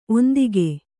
♪ ondige